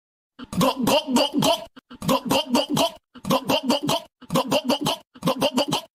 Gulp Gulp Gulp Gulp Meme.mp3